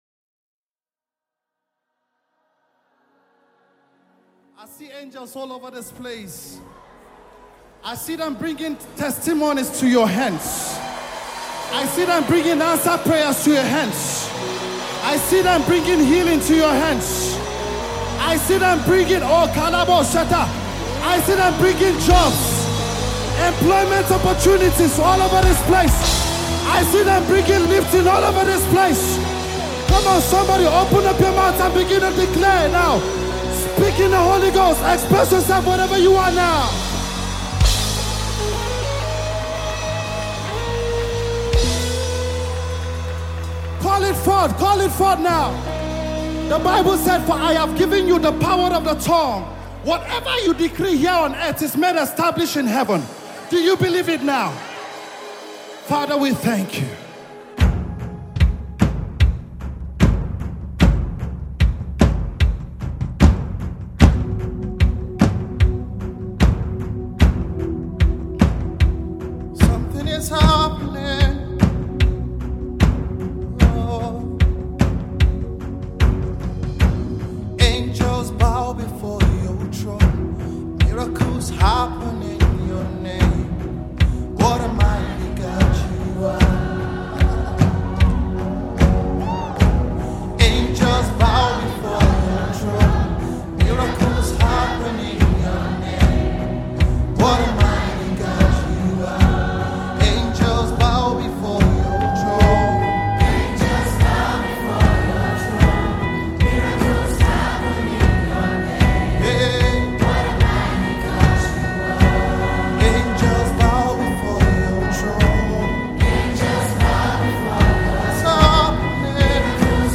Nigerian gospel minister
spirit-lifting single